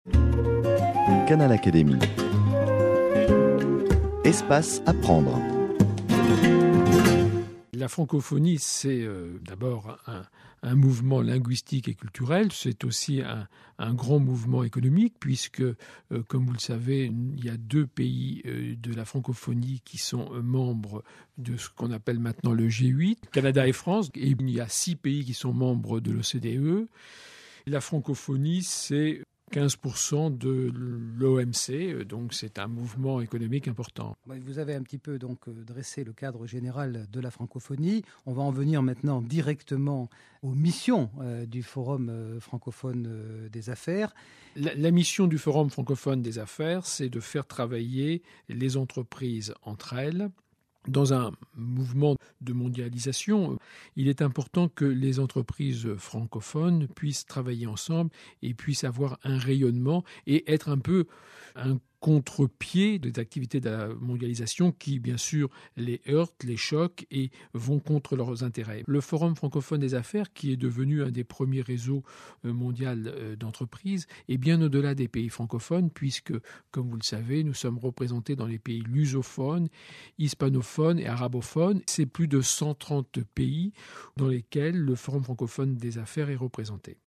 D’après l’entretien, en quoi la Francophonie a-t-elle un poids économique important ?